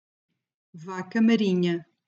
(pronunciação); Peixe-mulher